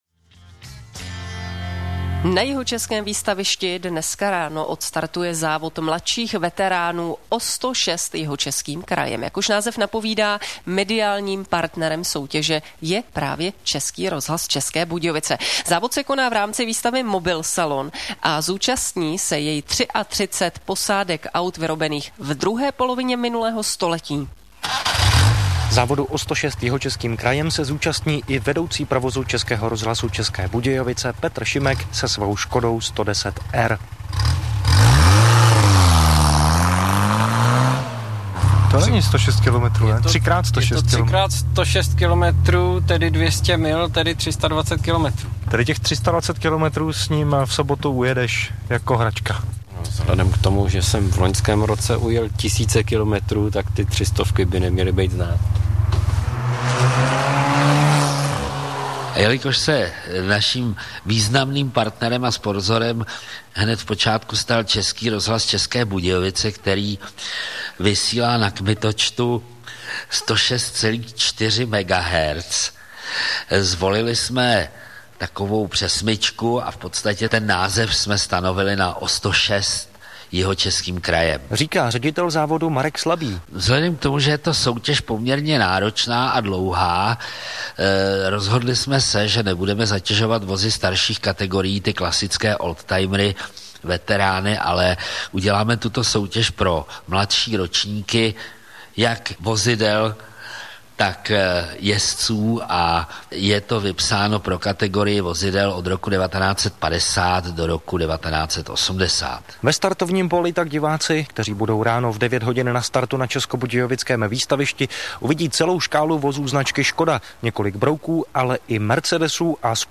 Pro ty, kteří jste tedy neposlouchali, přidávám nahrávky z Českého rozhlasu 8-)
2014-rozhlas-reportaz.mp3